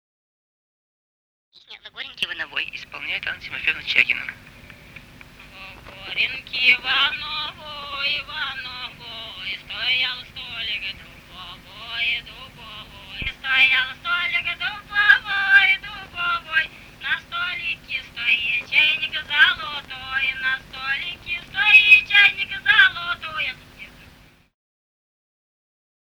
Русские народные песни Владимирской области 8. Во горенке во новой (свадебная) д. Тереховицы Муромского района Владимирской области.